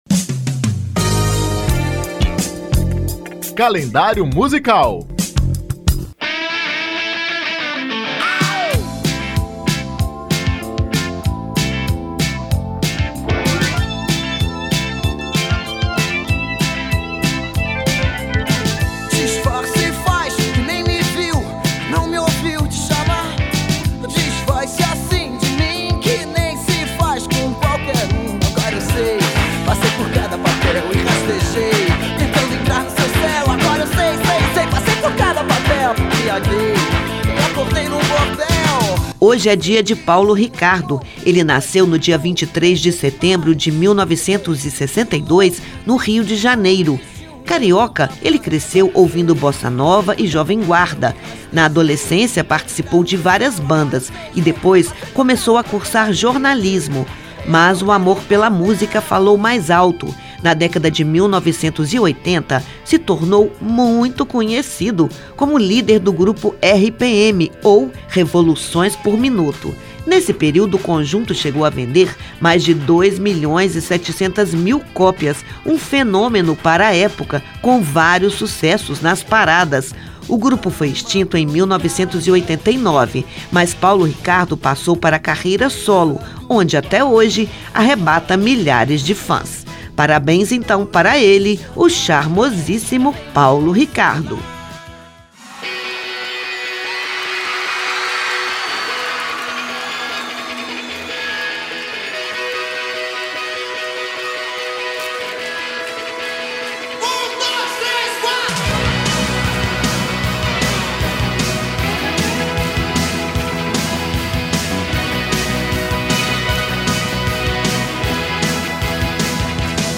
Com sua inconfundível voz rouca e seu charme todo especial, o cantor, que foi líder do RPM, tem milhares de fãs em todo o país!